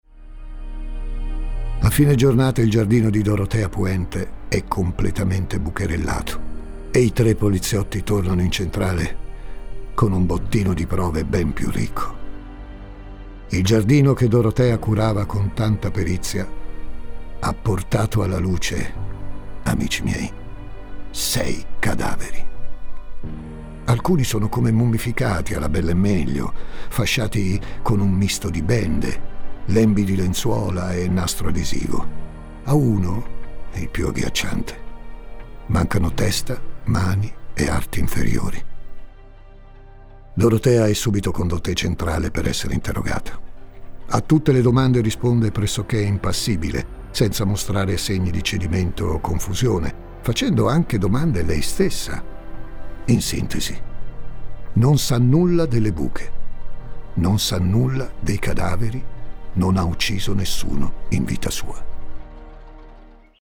Voce Narrante
Voce della protagonista